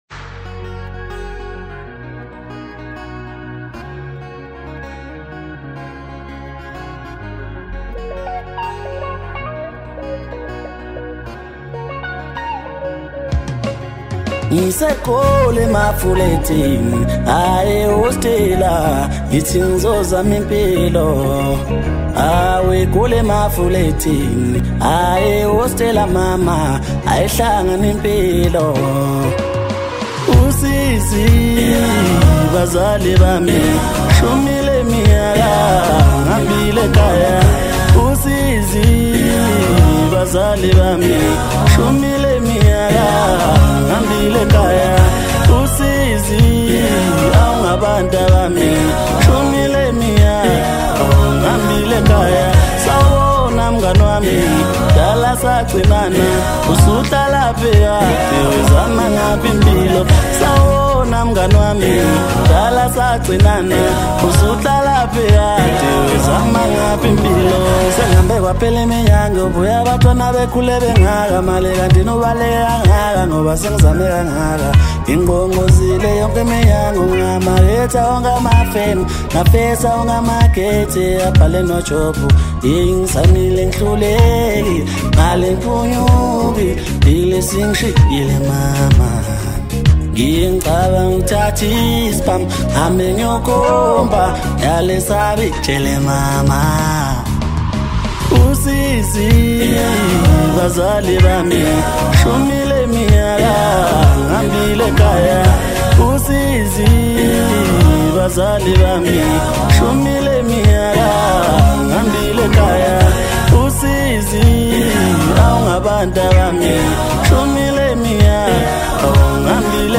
Home » Maskandi
South African singer